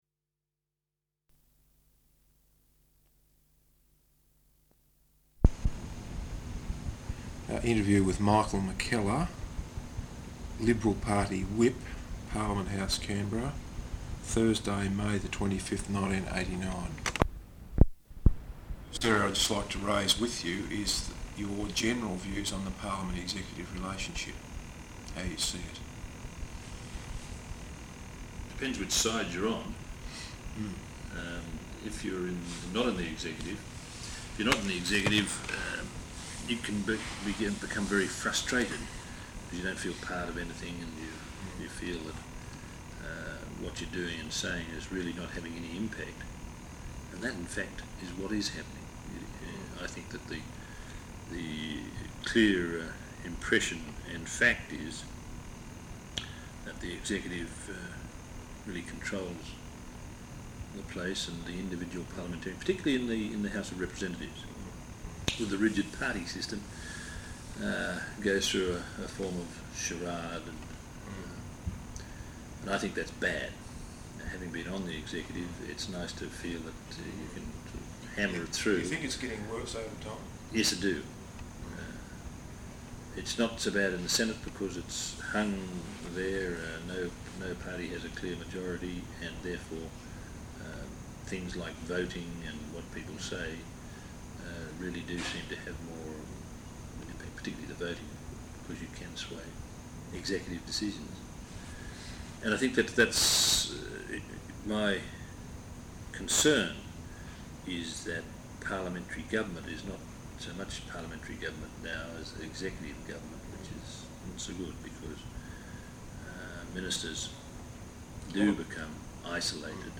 Interview with Michael Mackellar, Liberal Party Whip, Parliament House Canberra, Thursday May 25th 1989.